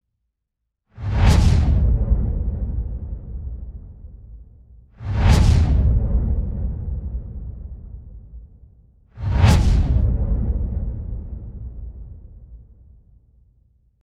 Звуки переходов
1. Низкочастотные эффекты переходных процессов n2. Эффекты низких частот при переходах n3. Переходные явления в низкочастотной области n4. Низкочастотные особенности переходов n5. Влияние переходов на низкие частоты